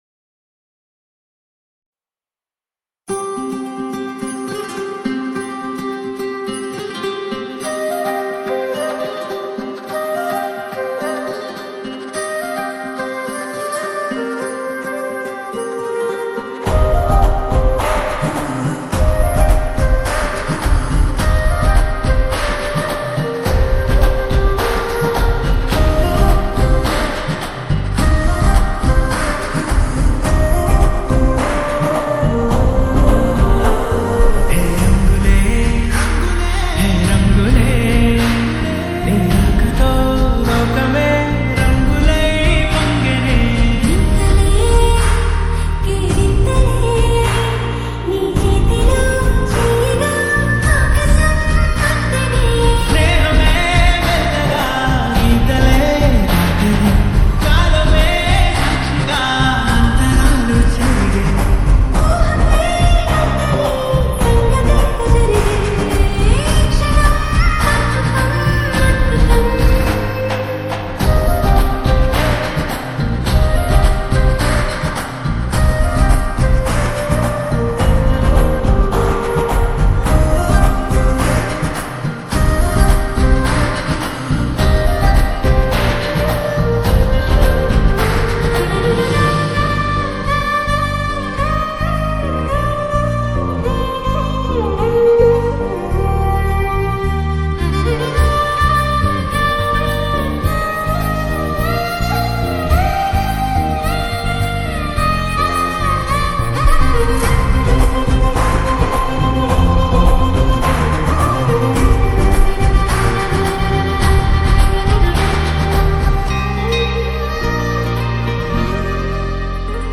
ALL TAMIL DJ REMIX